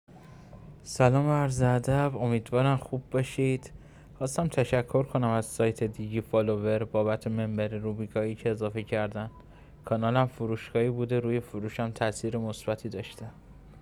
ویس مشتریان عزیزمان با صدای خودشان در مورد سایت دیجی فالوور